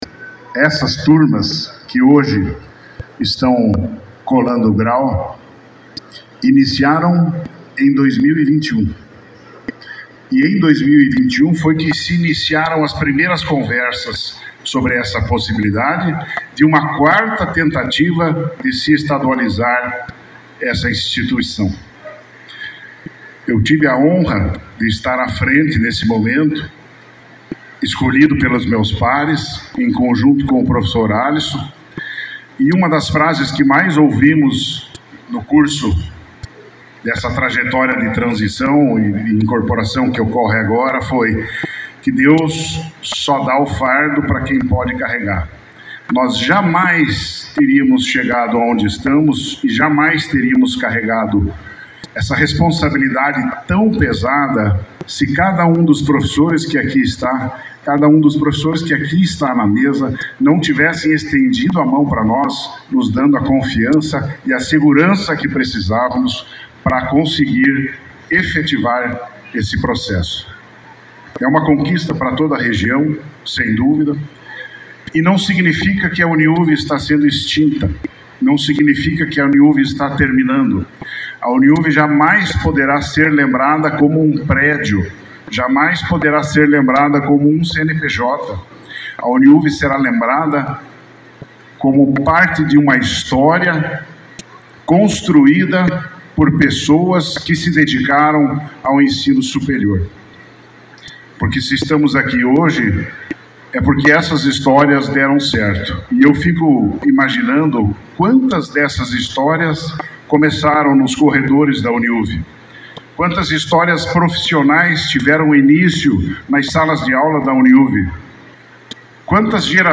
A última turma do Centro Universitário de União da Vitória (UNIUV), agora incorporado à Universidade Estadual do Paraná (Unespar), celebrou a colação de grau e o tradicional baile de formatura nas dependências do Wooden Hall.